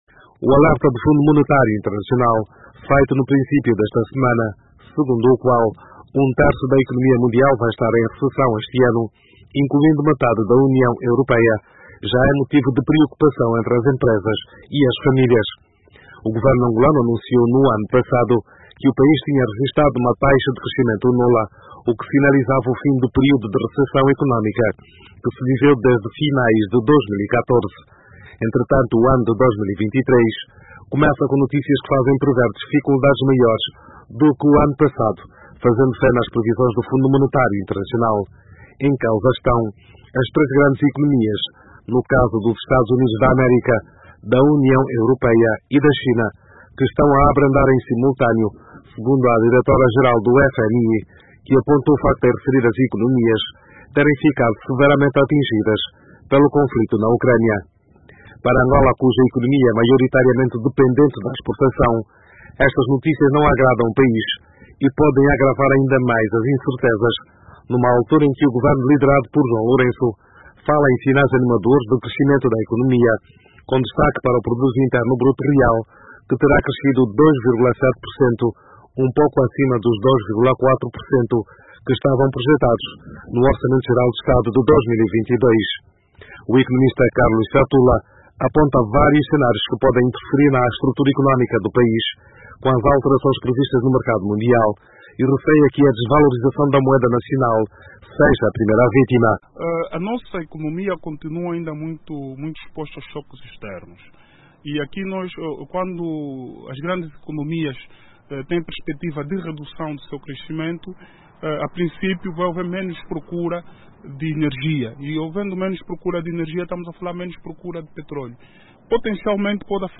ouvimos os economistas